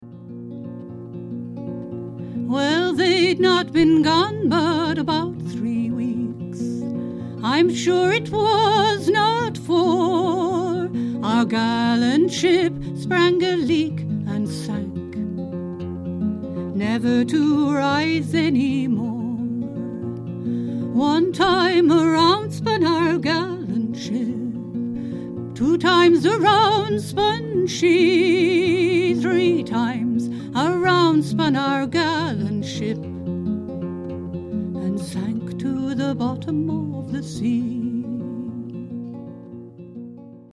Plays a mixture of traditional and contemporary folk and country music.